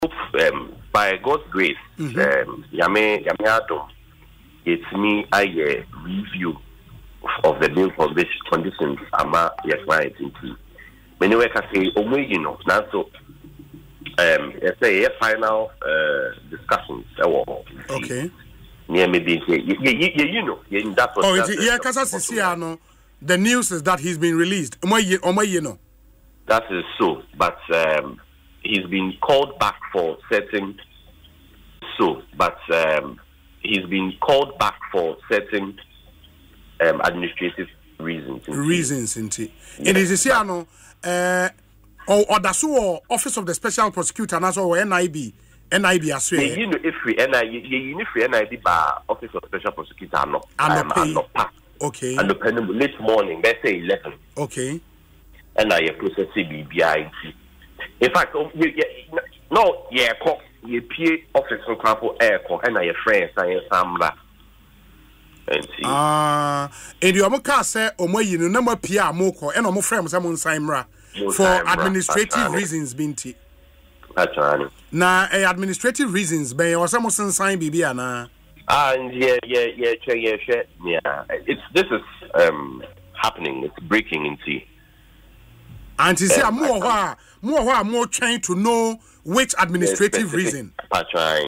Speaking in an interview on Asempa FM’s Ekosii Sen